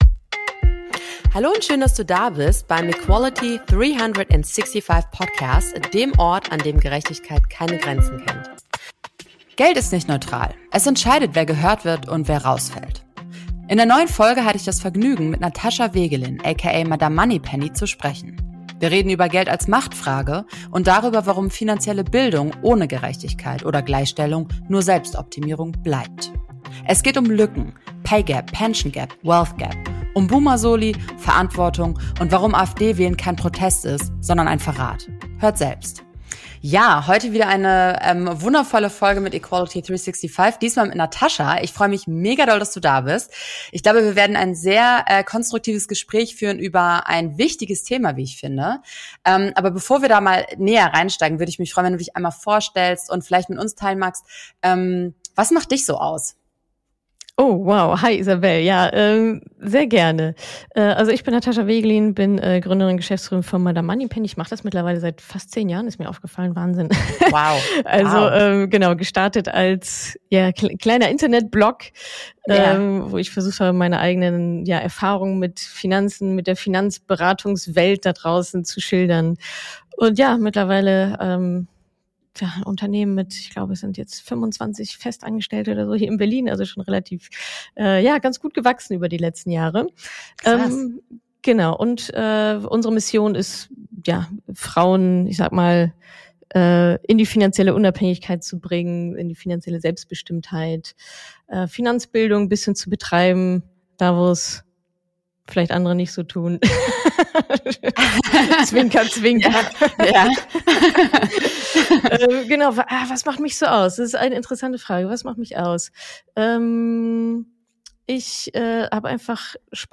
Ein Gespräch über Macht, Geldtabus – und die Frage, wie gerecht eine Gesellschaft sein kann, in der Kapital Zugehörigkeit definiert.